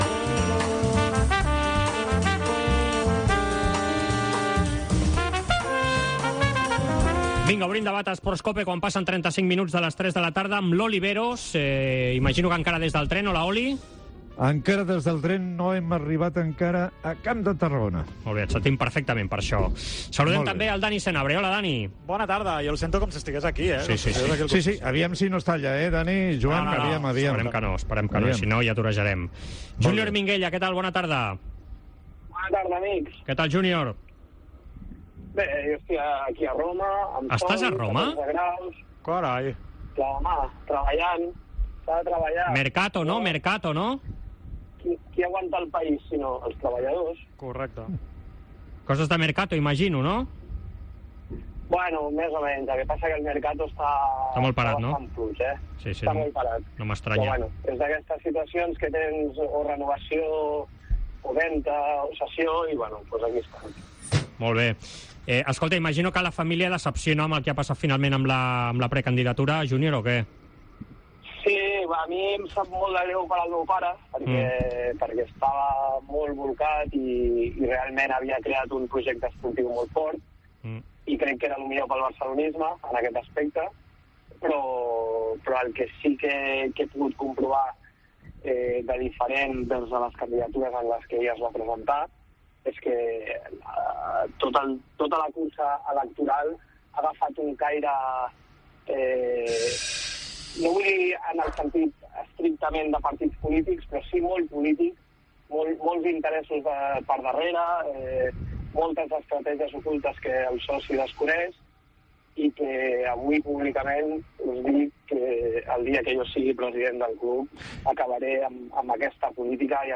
debat